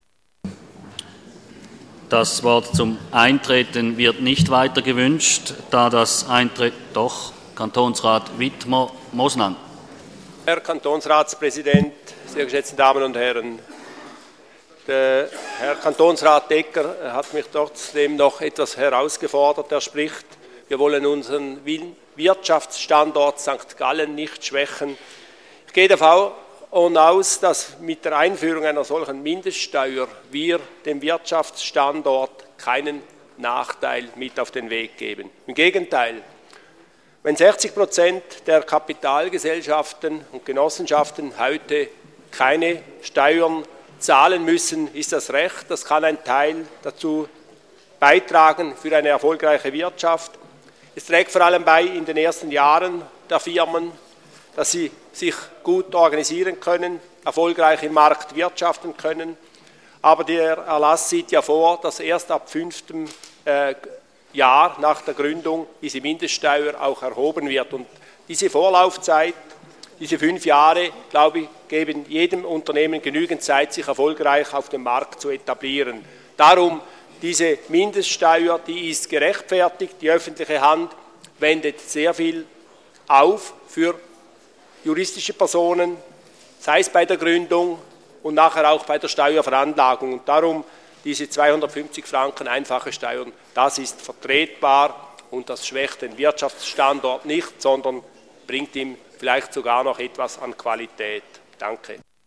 Session des Kantonsrates vom 24. und 25. Februar 2014